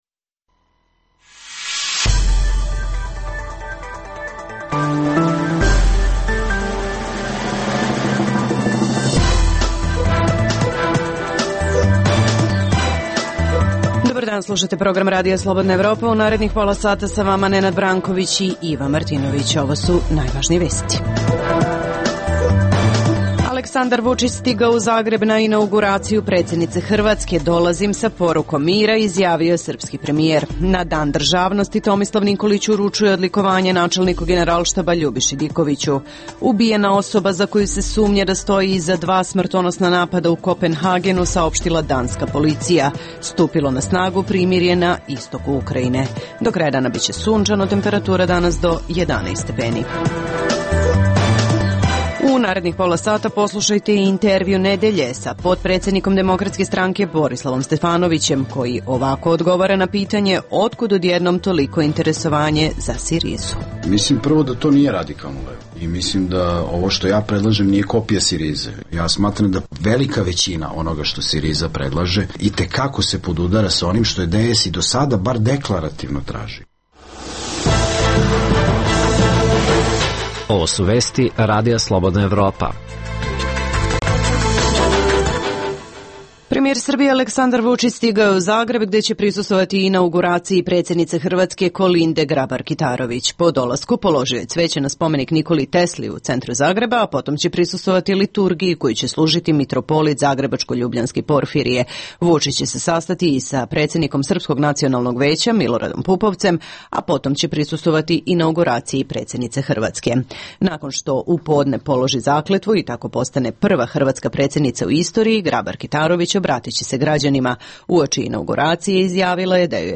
Poslušajte i Intervju nedelje sa potpredsednikom Demokratske stranke Borislavom Stefanovićem.